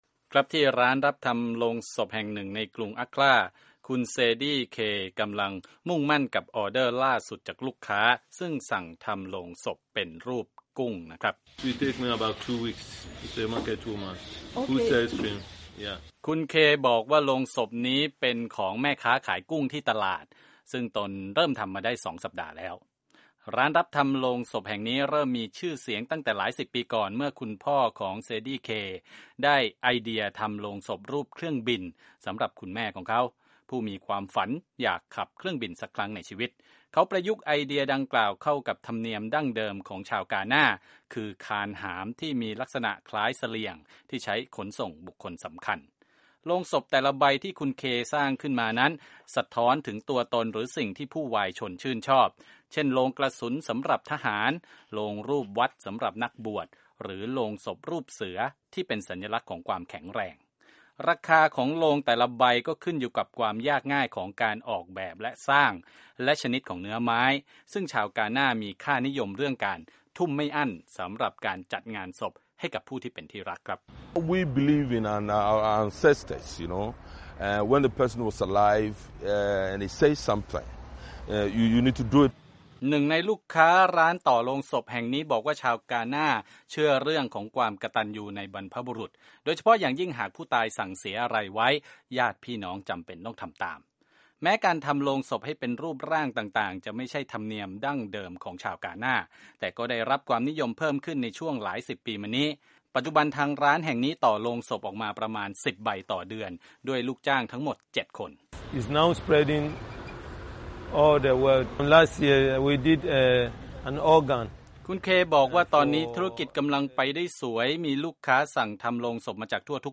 รายงานจากกรุงอัคครา กาน่า